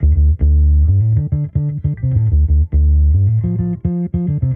Index of /musicradar/sampled-funk-soul-samples/105bpm/Bass
SSF_PBassProc1_105D.wav